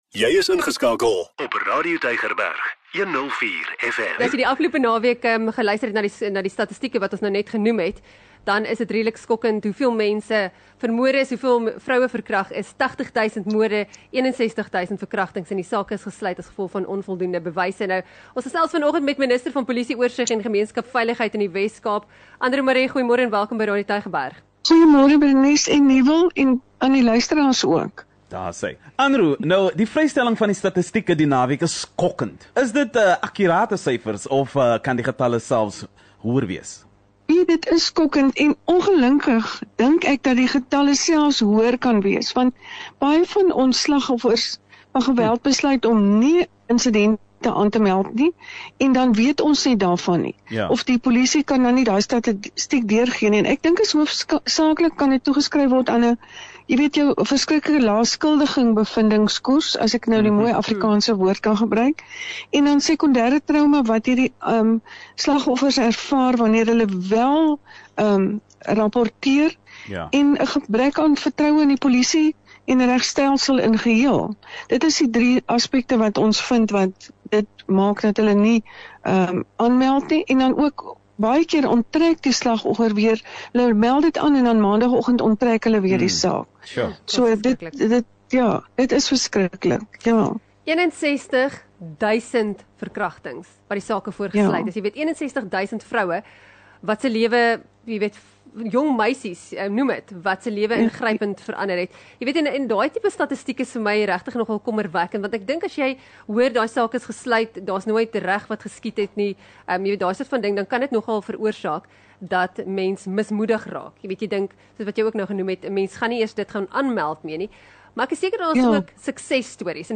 Ons het gesels met Anroux Marais, minister van polisie-oorsig en gemeenskapsveiligheid in die WesKaap. Sy het gepraat oor die statistiek van 80,000 moordsake en 61,000 verkragtingsake wat gesluit is vanaf 2018 weens onvoldoende bewyse.